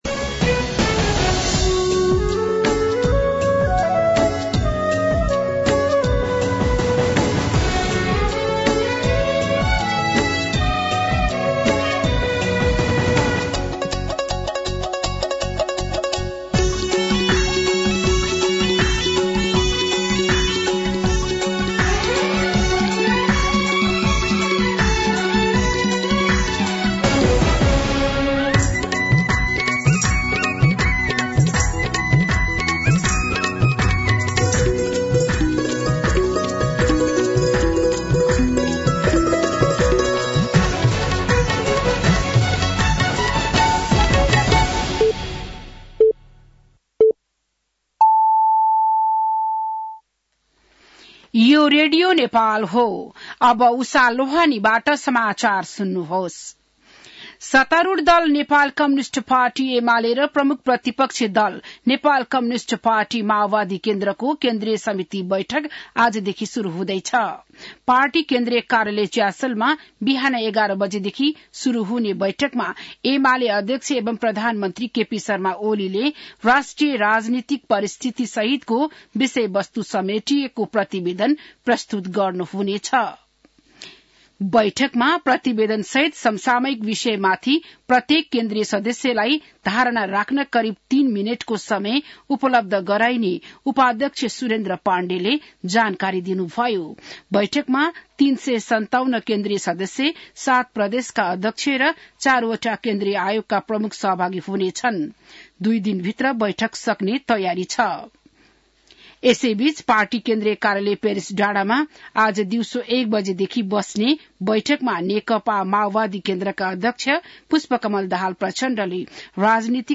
बिहान ११ बजेको नेपाली समाचार : २२ पुष , २०८१